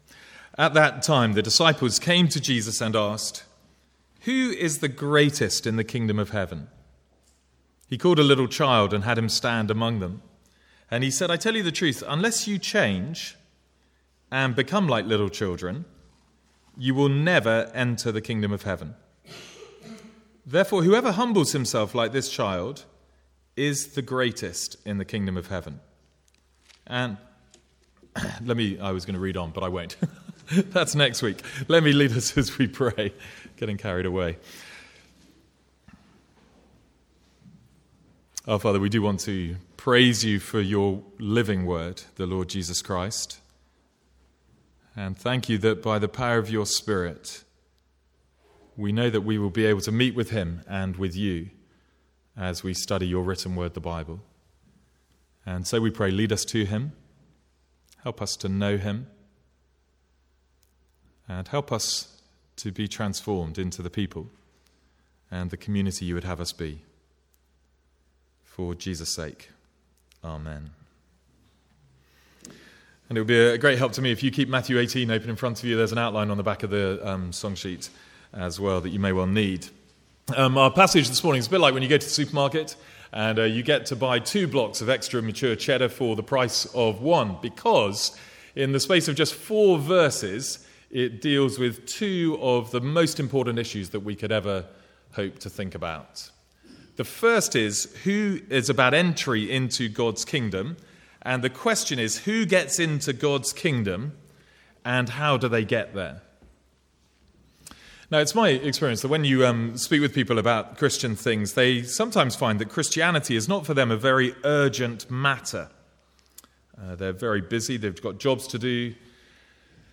From the Sunday morning series in Matthew.